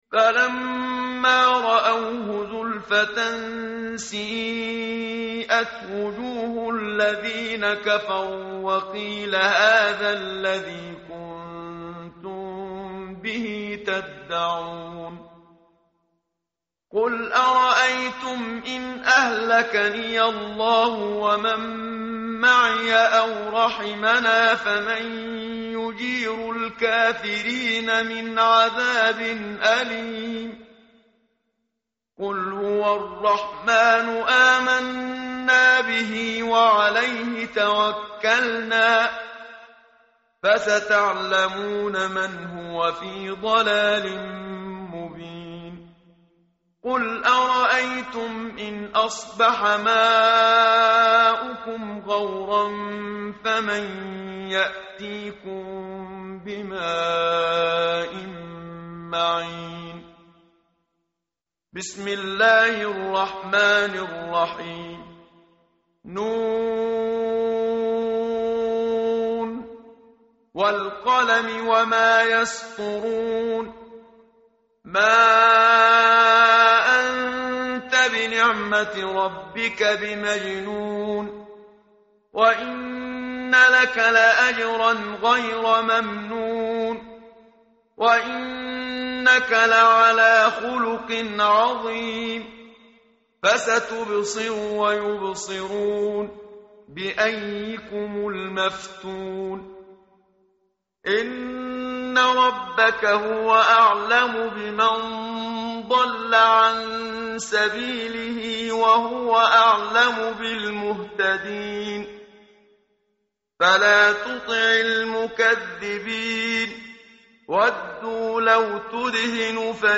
متن قرآن همراه باتلاوت قرآن و ترجمه
tartil_menshavi_page_564.mp3